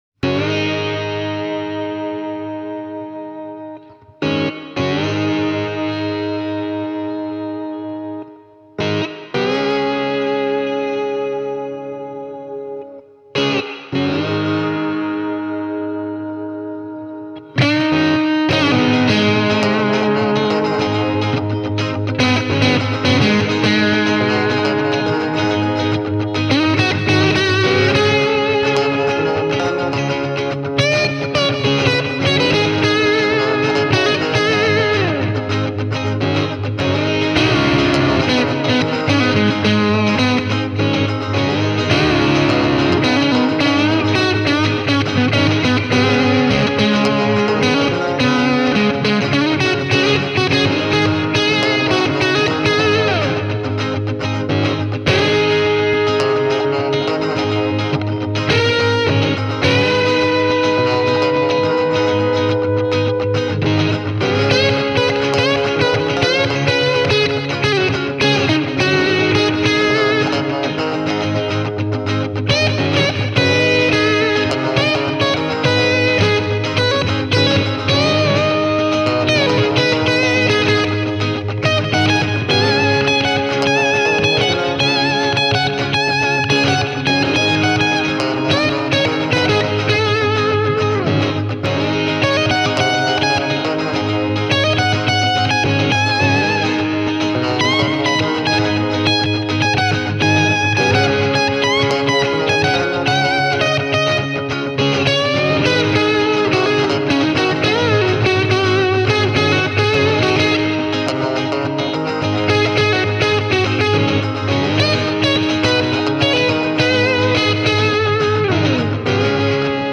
Demobiisiä varten mikitin kaksi täysputkikomboa (Juketone True Blood ja Bluetone Shadows Jr.) Shure SM57 -mikrofoneilla. Kaikki säröä tulee vahvistimista. Ainoa pedaali on biisin tremolo-osuuksissa käytetty uusi Bluetone Harmonic Tremolo.